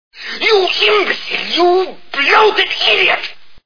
The Maltese Falcon Movie Sound Bites